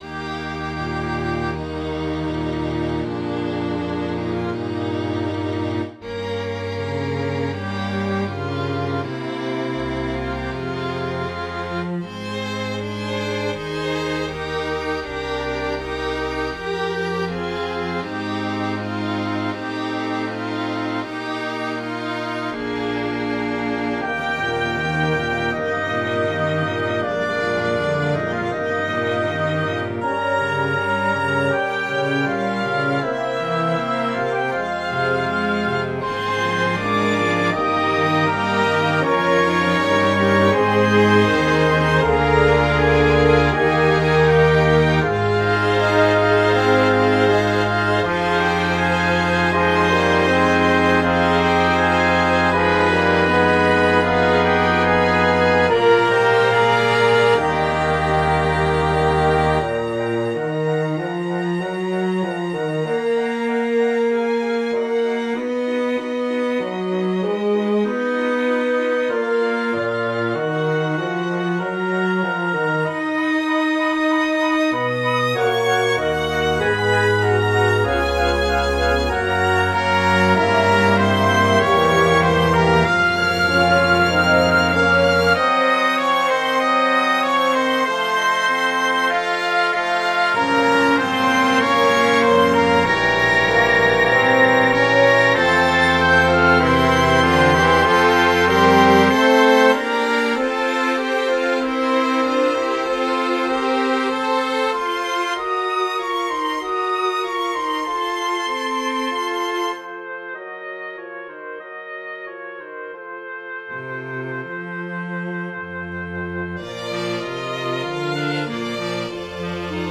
SYNTHESISED RECORDINGS LIBRARY
Orchestra